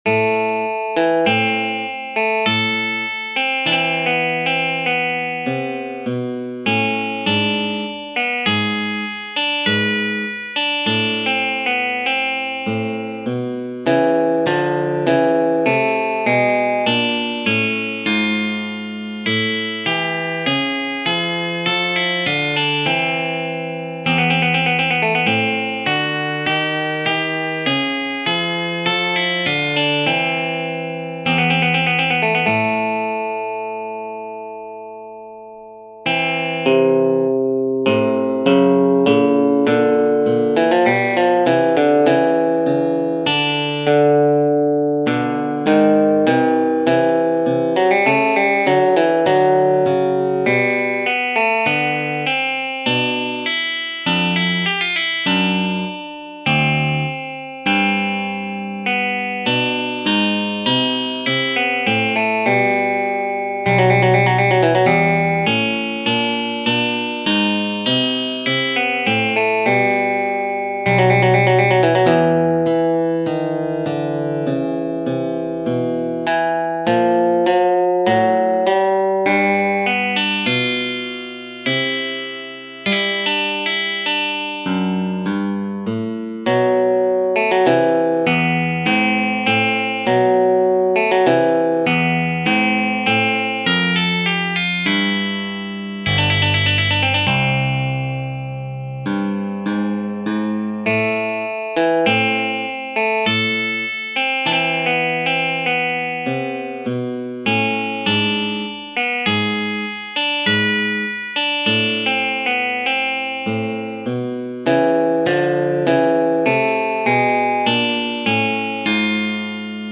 Arrangement in 41edo